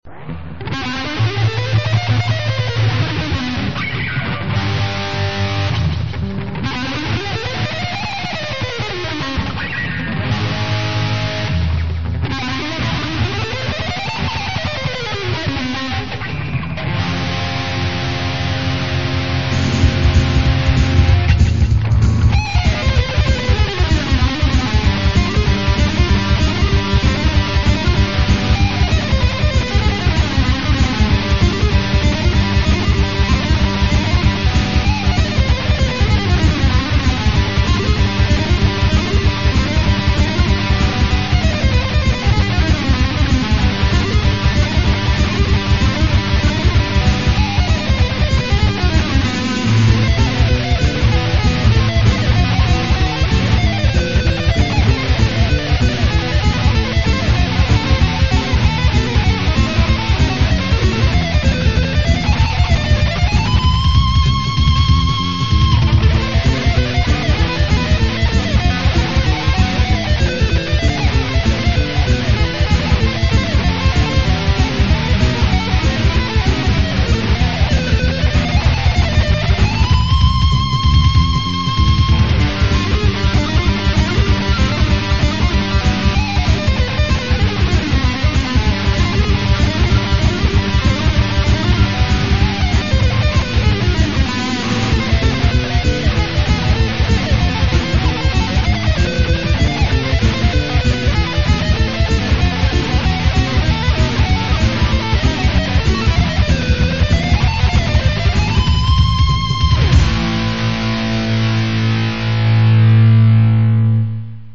ので、24歳くらいのときに録音したものを。